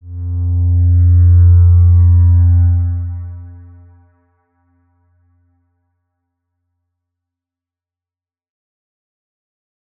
X_Windwistle-F1-pp.wav